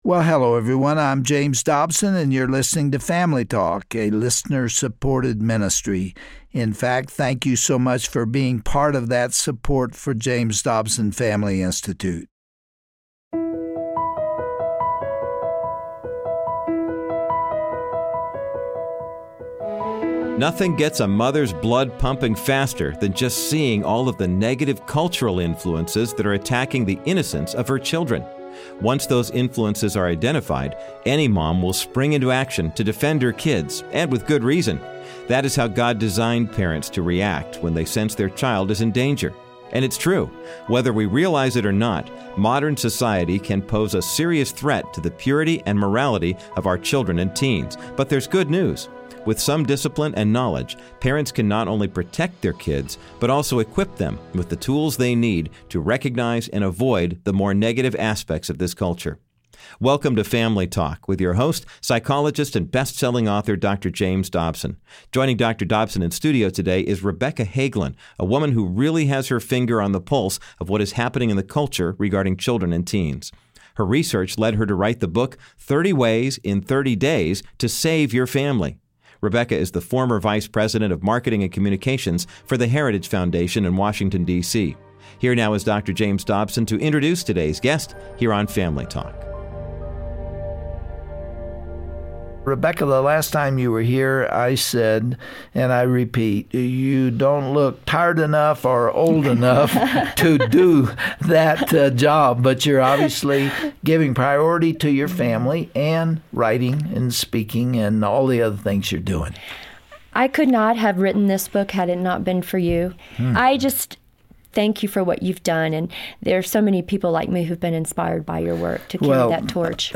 Host Dr. James Dobson